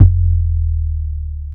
OZ - 808 3.wav